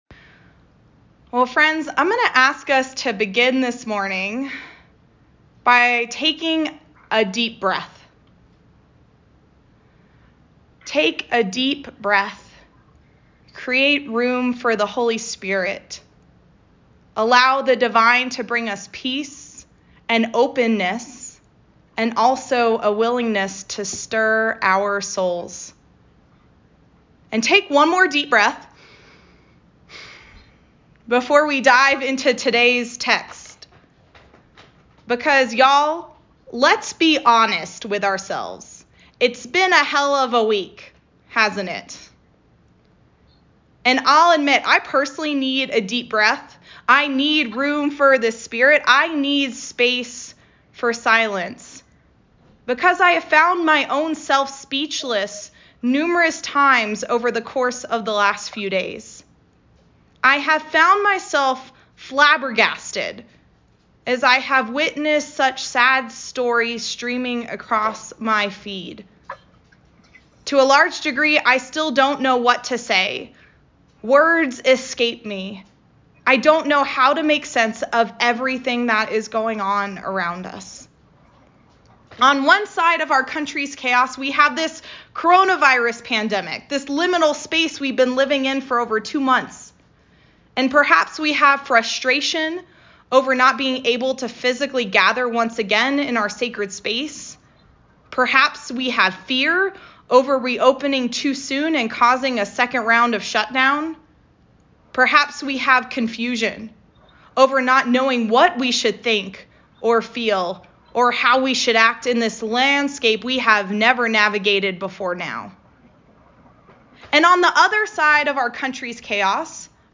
a sermon for the feast of Pentecost delivered May 31, 2020